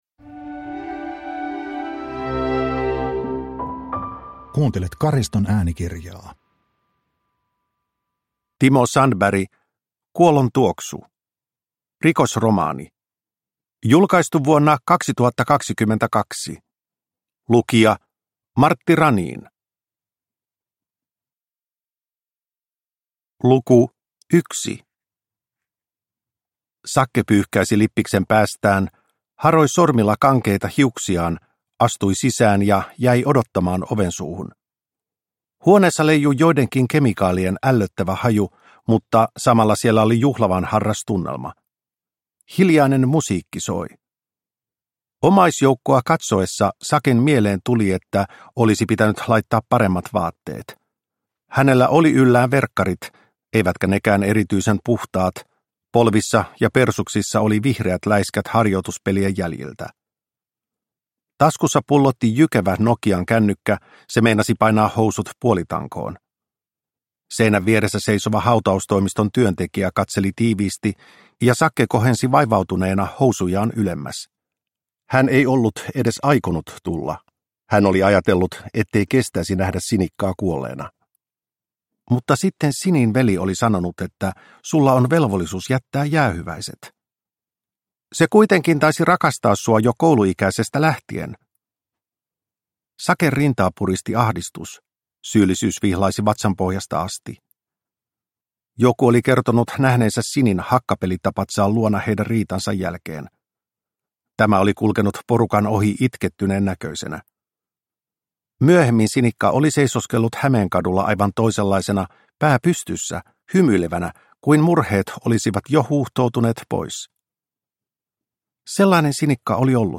Kuolontuoksu – Ljudbok – Laddas ner